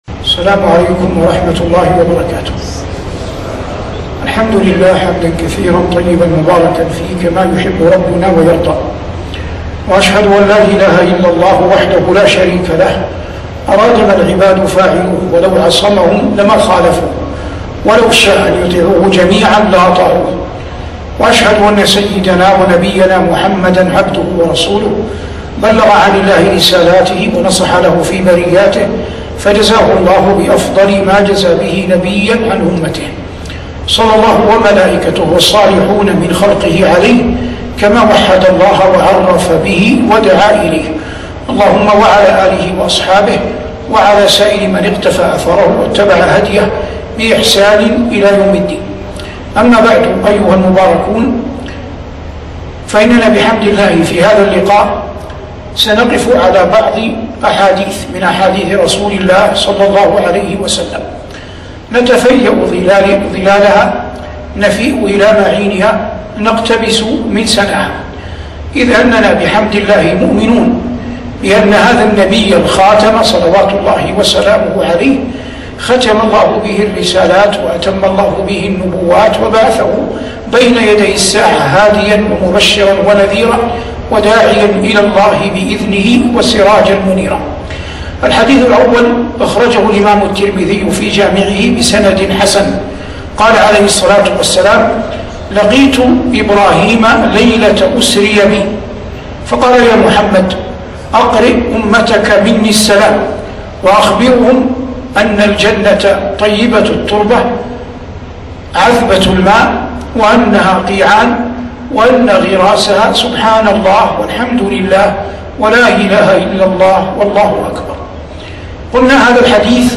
محاضرة بعنوان "من كنوز السنة" بملتقى الباحة الدعوي العاشر 1438هـ